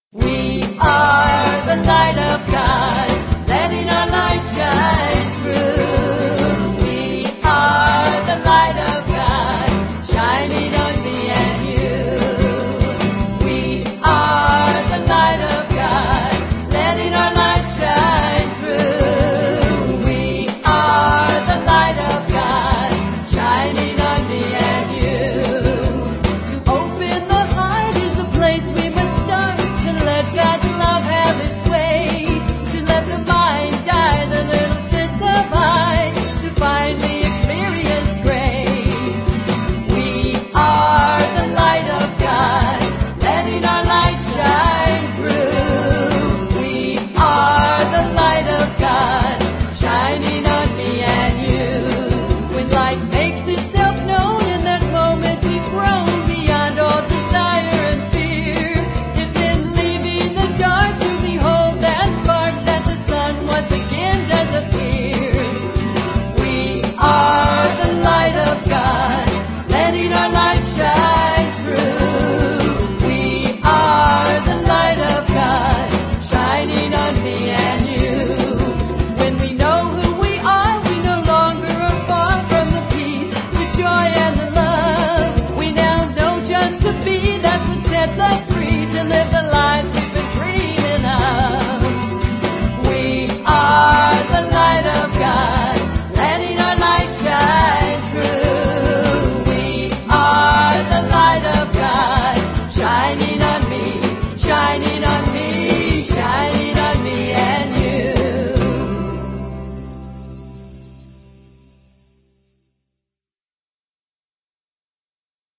8 Beat / Keherwa / Adi
Fast
4 Pancham / F
1 Pancham / C